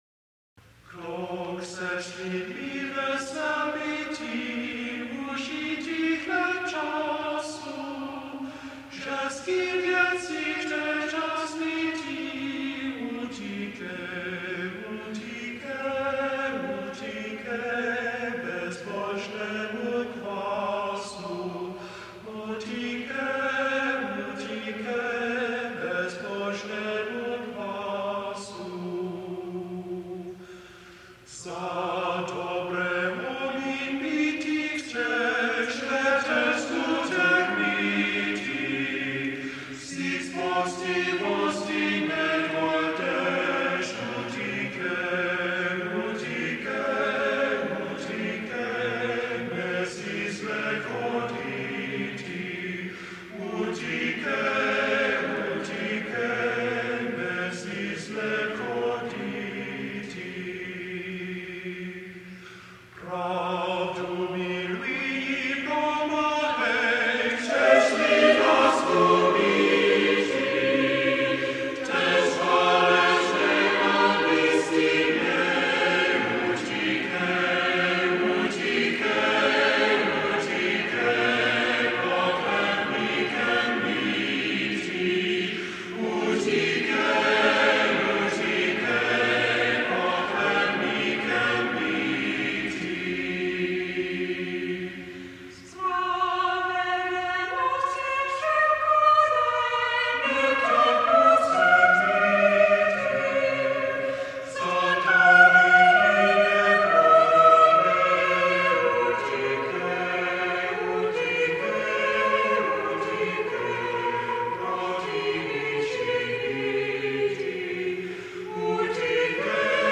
The original runs to ten stanzas . . . six are sung here.